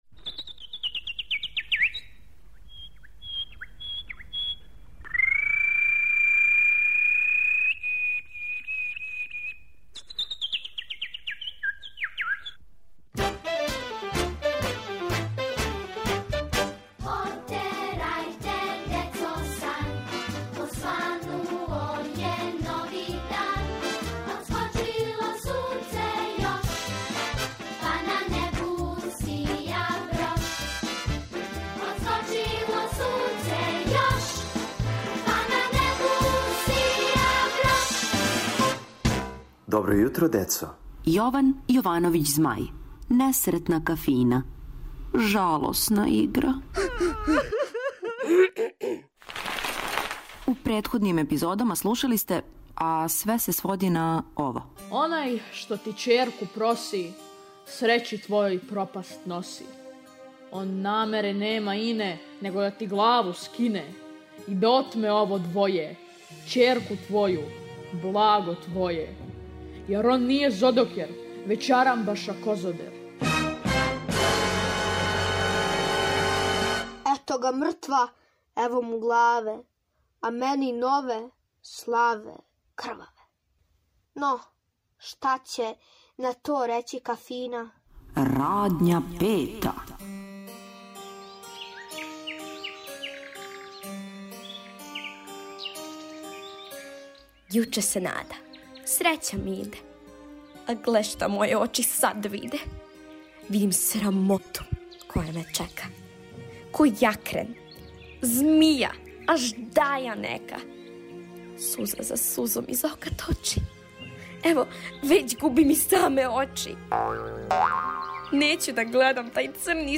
Слушате последњи део драме за децу Јована Јовановића Змаја "Несретна Кафина".